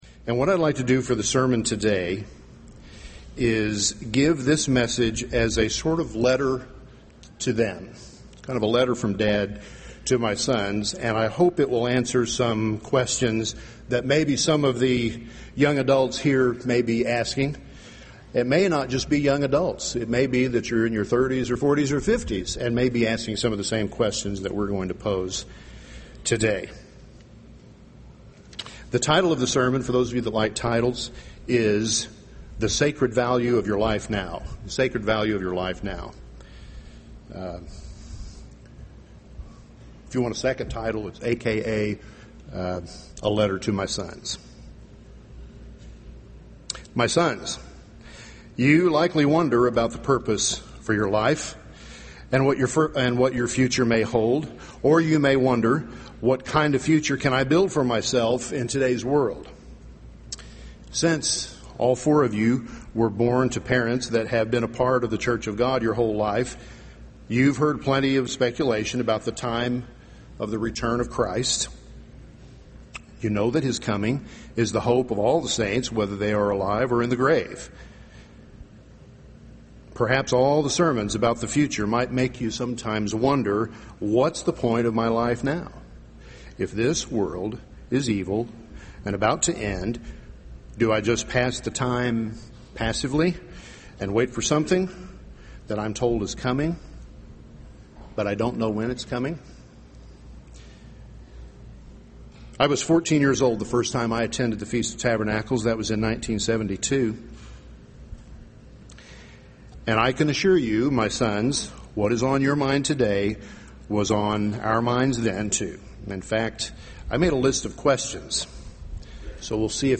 Given in Wisconsin Dells, Wisconsin 2010